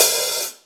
paiste hi hat2 half.wav